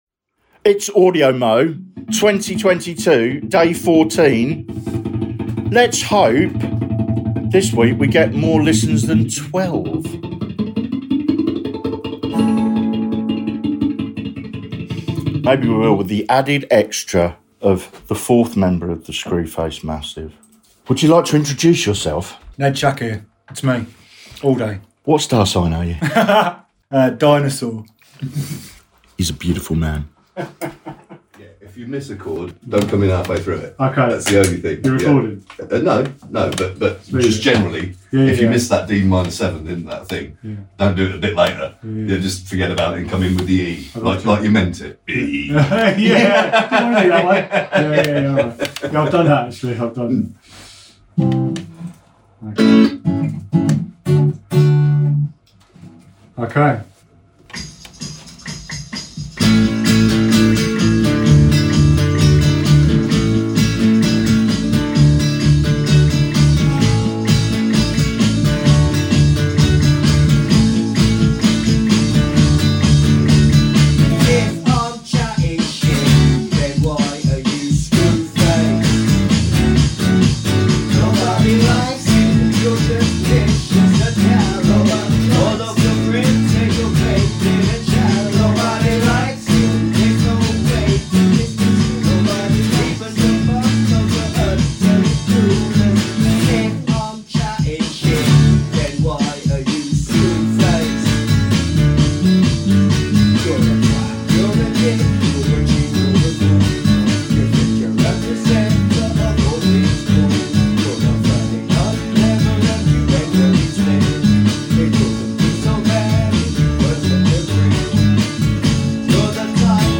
This week's Screwface Massive practice with added dinosaur.